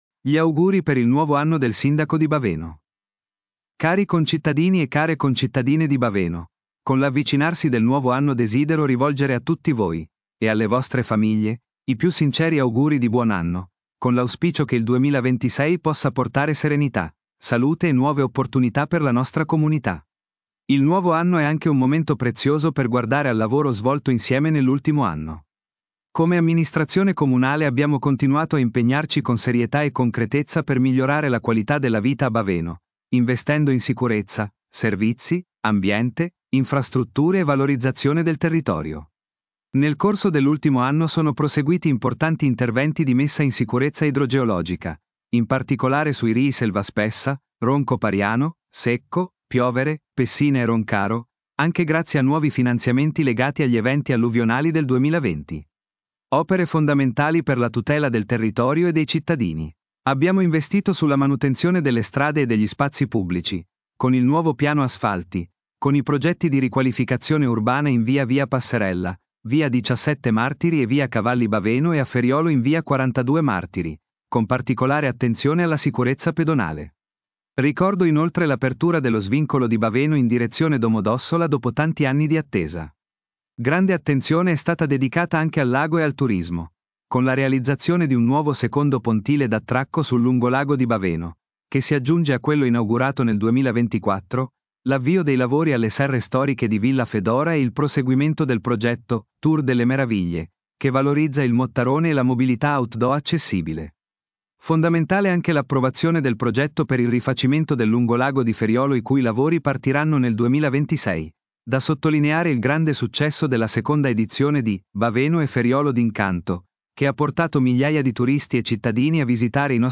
Gli auguri per il nuovo anno del sindaco di Baveno